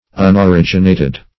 Search Result for " unoriginated" : The Collaborative International Dictionary of English v.0.48: Unoriginated \Un`o*rig"i*na`ted\, a. 1. Not originated; existing from all eternity.
unoriginated.mp3